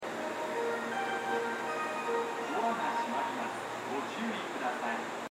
スピーカーはＴＯＡ型が設置されており音質も高音質です。スピーカーの高さも低めですが 音量がやや小さめです。
発車メロディーフルコーラスです。